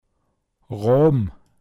pinzgauer mundart
Raben Rå(b)m